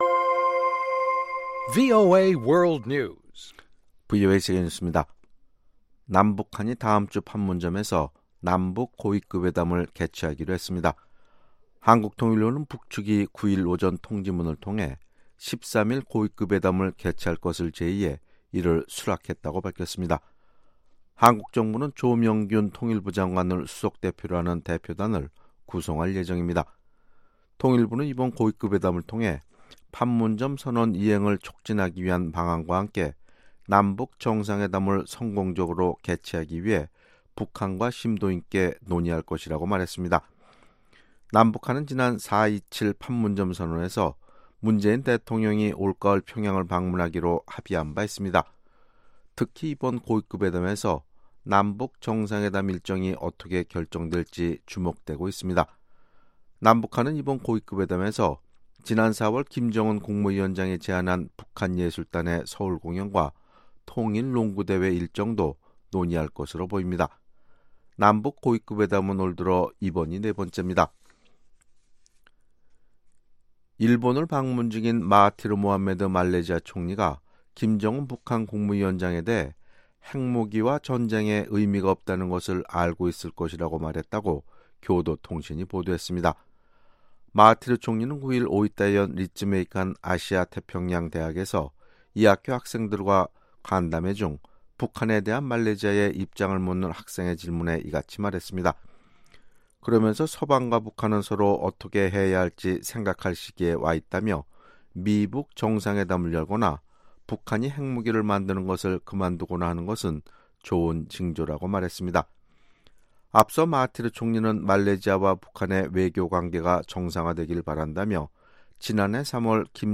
VOA 한국어 아침 뉴스 프로그램 '워싱턴 뉴스 광장' 2018년 8월 10일 방송입니다. 트럼프 대통령은 북한을 완전히 비핵화시키고 핵 문제가 다시 떠오르지 않게 되기를 바라고 있다고 미 국무부가 밝혔습니다. 평양의 현재 모습을 기록한 글과 사진을 담은 책이 한국에서 출간됐습니다.